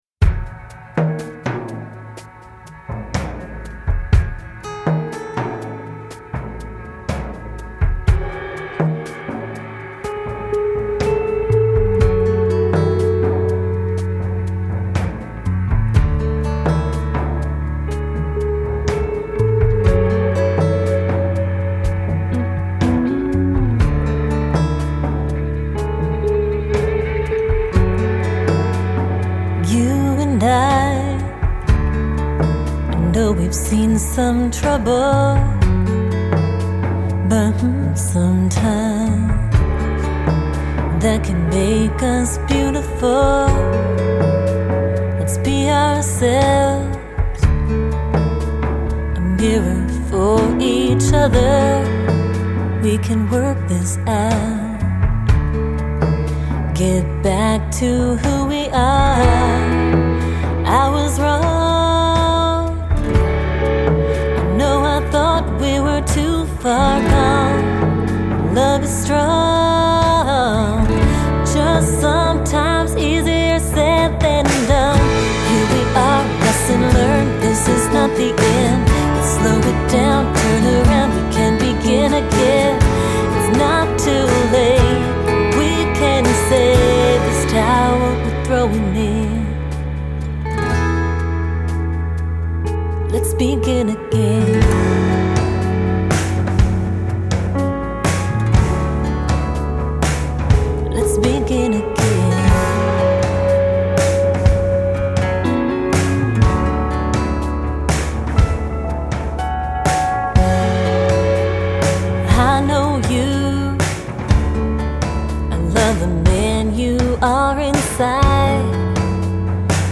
singer/songwriter